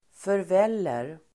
Folkets service: förvälla förvälla verb, parboil Grammatikkommentar: A & x Uttal: [förv'el:er] Böjningar: förvällde, förvällt, förväll, förvälla, förväller Definition: koka (grönsaker etc) hastigt i vatten parboil , förvälla